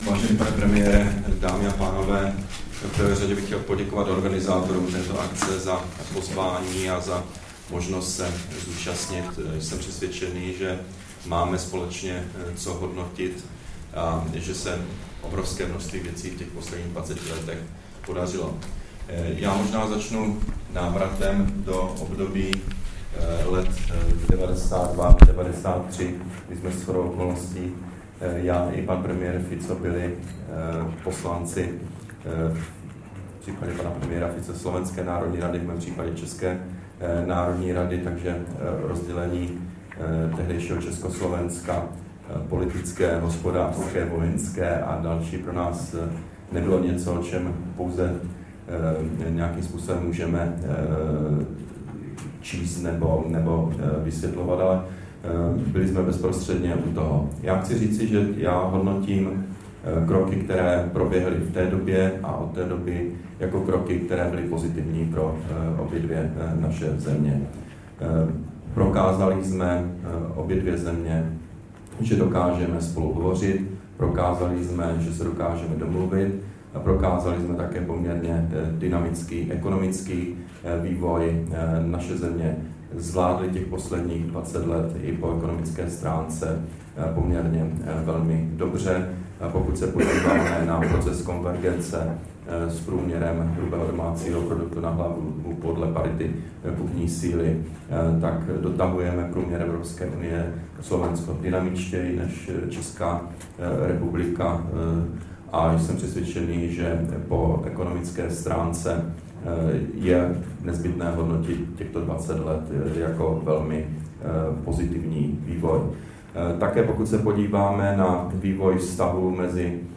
Vystoupení premiéra Petra Nečase na diskusním foru HNClubu v Bratislavě, 6. června 2013